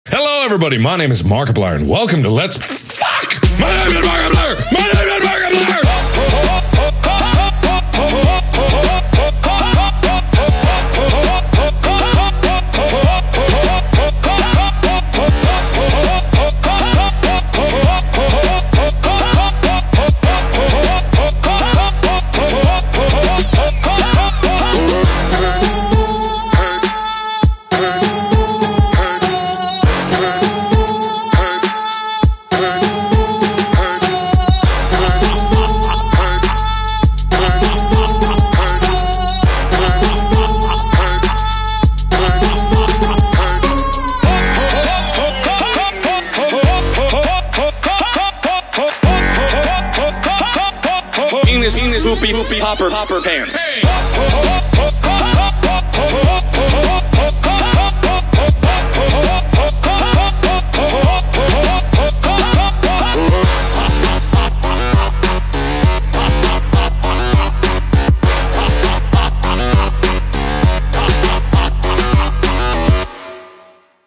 BPM100
Audio QualityPerfect (Low Quality)